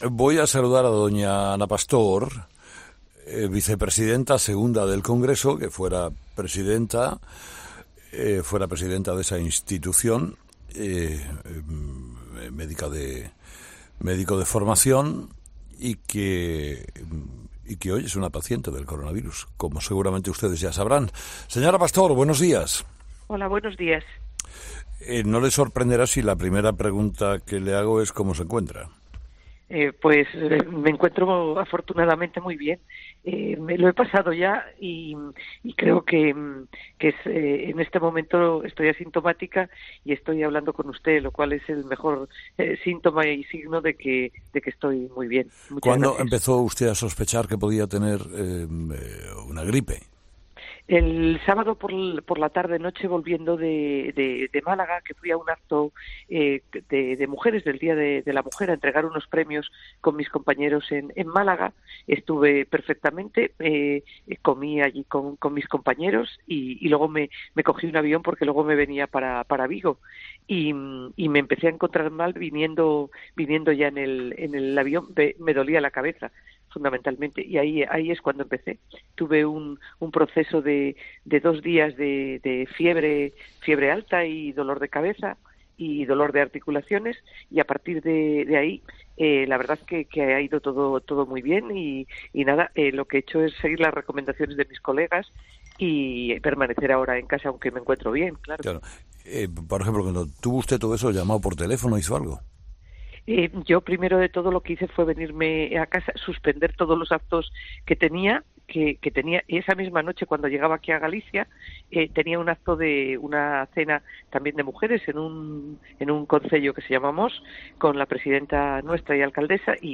En una entrevista este jueves en "Herrera en COPE" , Ana Pastor ha confirmado que se encuentra bien y que en estos momentos está "asintomática" .